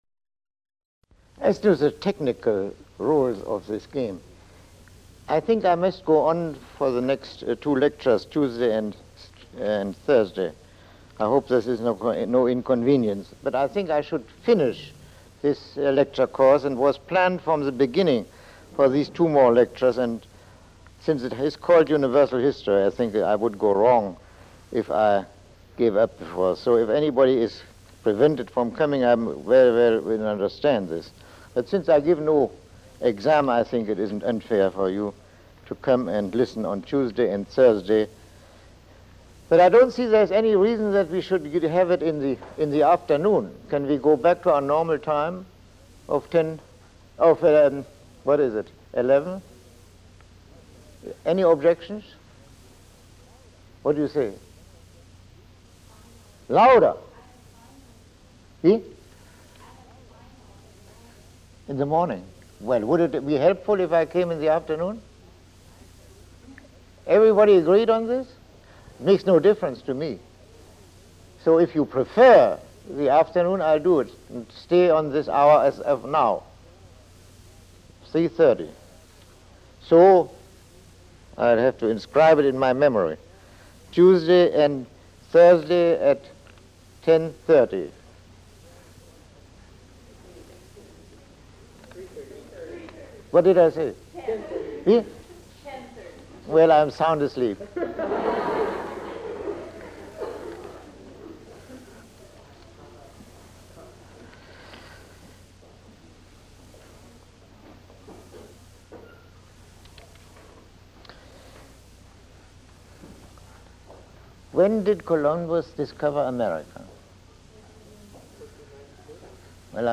Lecture 18